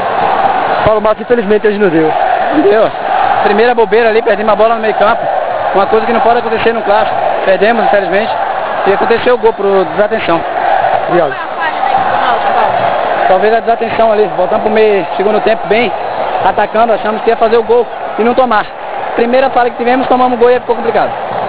ENTREVISTA *Em Audio